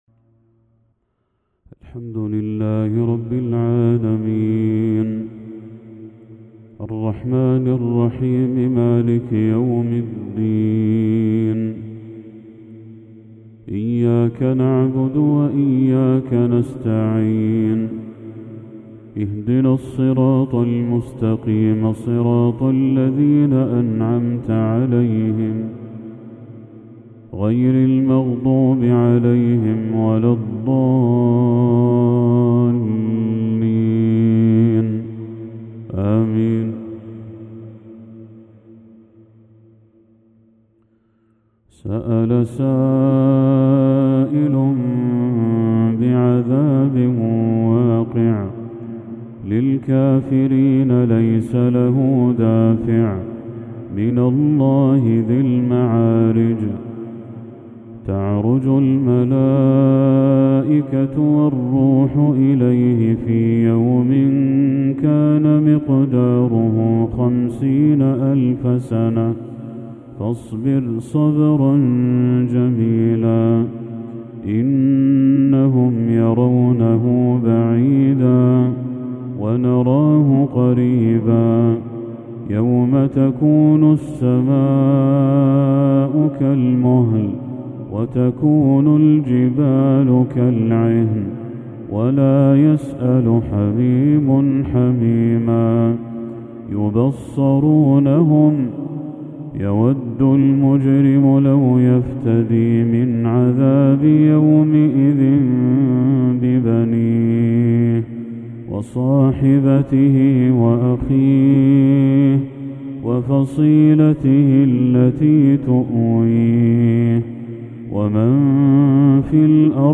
تلاوة بديعة للشيخ بدر التركي سورة المعارج كاملة | عشاء 18 ذو الحجة 1445هـ > 1445هـ > تلاوات الشيخ بدر التركي > المزيد - تلاوات الحرمين